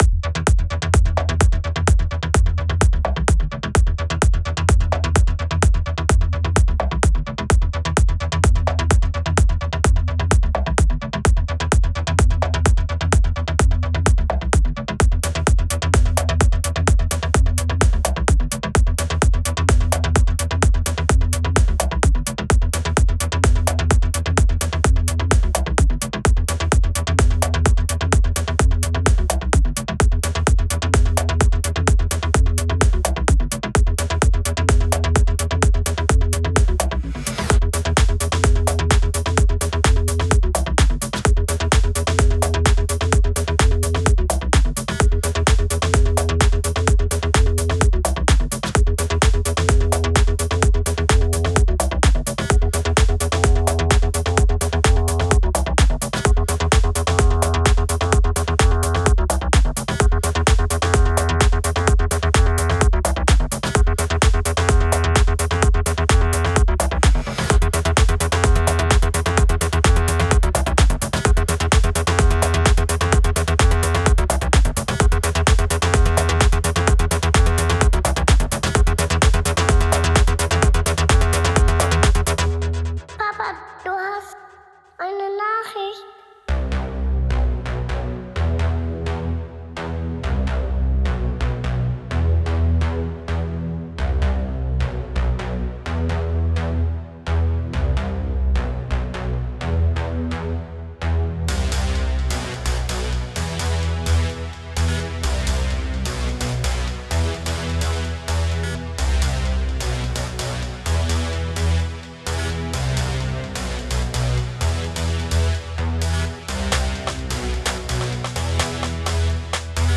Da ich noch überhaupt keine Ahnung vom mastering habe, habe ich ein preset benutzt...